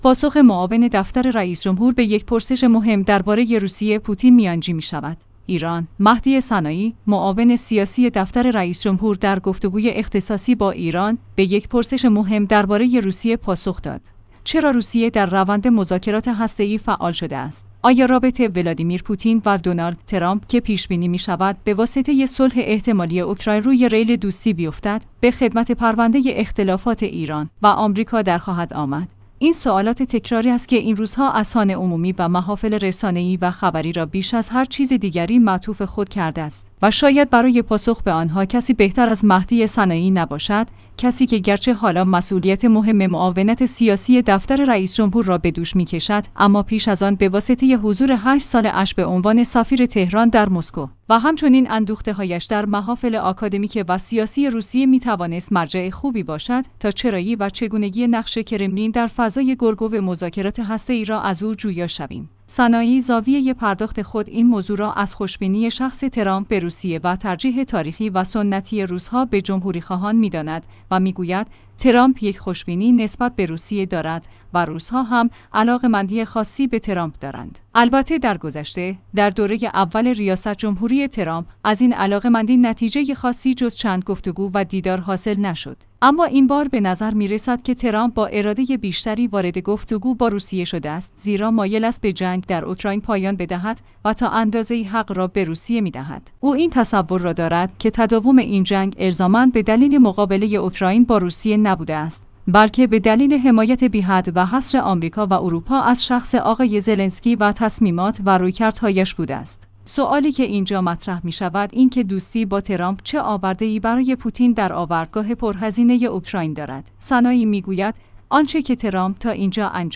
ایران/ مهدی سنایی، معاون سیاسی دفتر رئیس‌جمهور در گفت‌وگوی اختصاصی با «ایران» به یک پرسش مهم درباره روسیه پاسخ داد. چرا روسیه در روند مذاکرات هسته‌ای فعال شده است؟